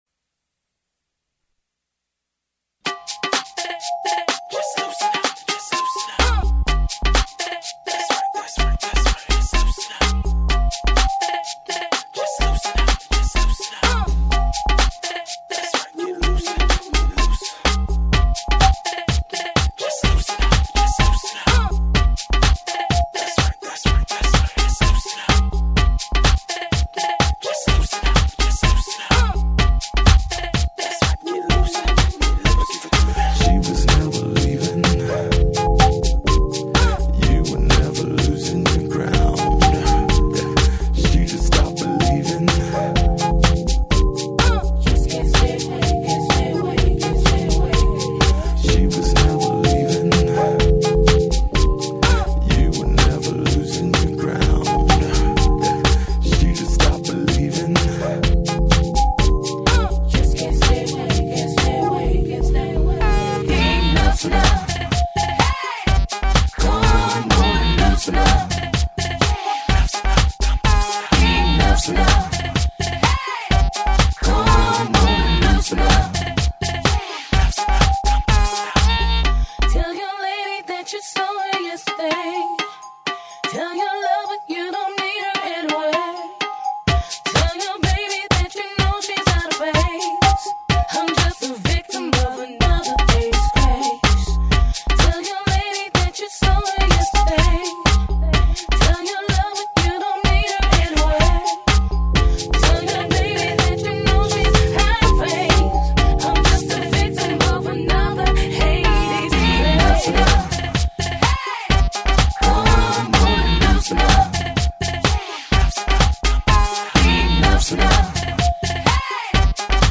funky, groovy & freaky underground house mixes